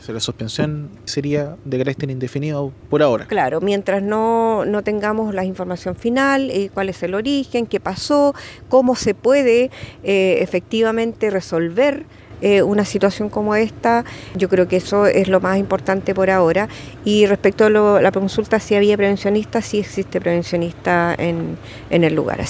Ante ello, la seremi del Trabajo en Bío Bío, Sandra Quintana, señaló que el astillero contaba -al momento del hecho- con un prevencionista de riesgos.